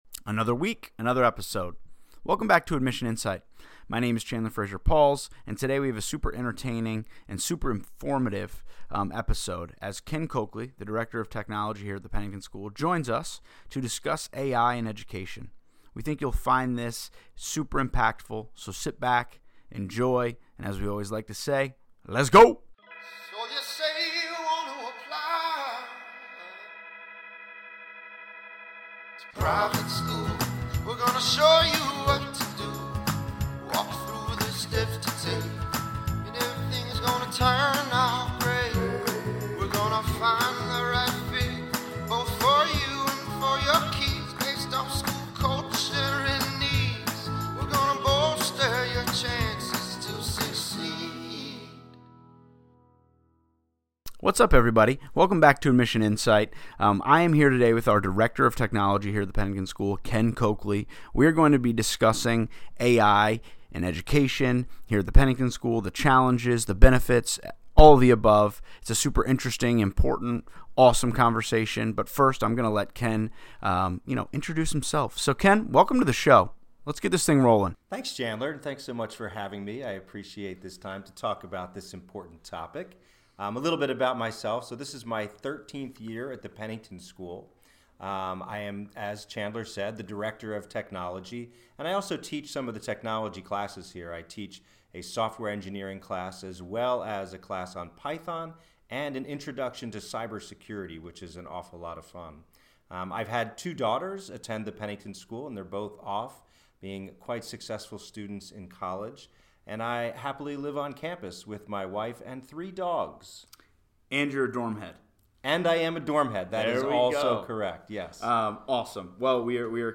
We know you will really enjoy this conversation.